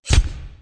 traphit_7.ogg